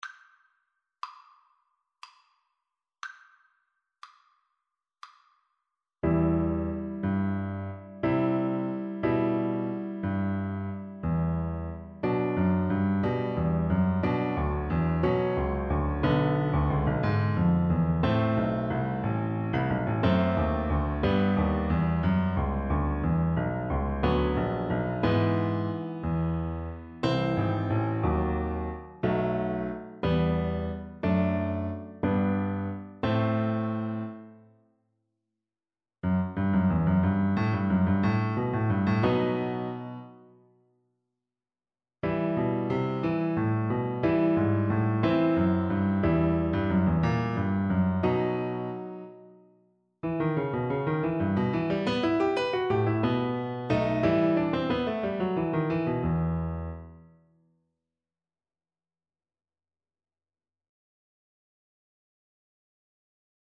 Cello
E minor (Sounding Pitch) (View more E minor Music for Cello )
Allegro giocoso (View more music marked Allegro giocoso)
9/8 (View more 9/8 Music)
Classical (View more Classical Cello Music)